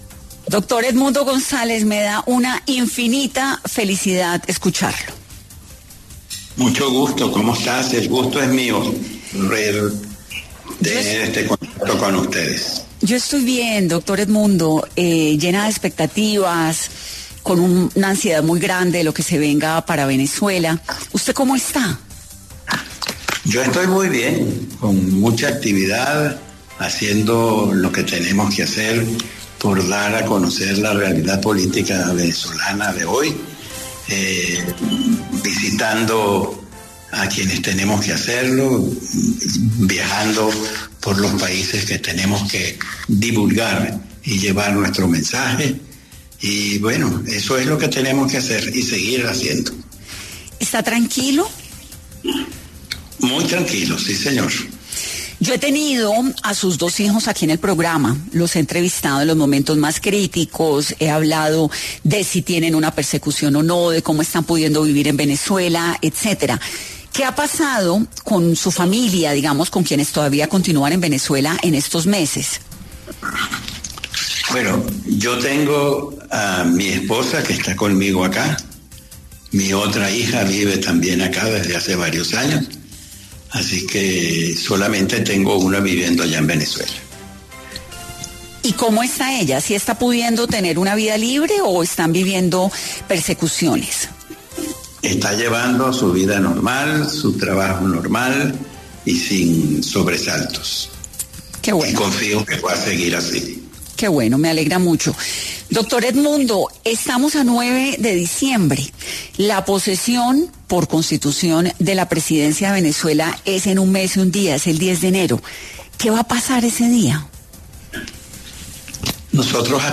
En 10AM de Caracol Radio estuvo Edmundo González, presidente electo de Venezuela, quien habló de la situación en su país y si regresará el 10 de enero a posicionarse como Presidente.